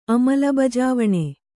♪ amalabajāvaṇe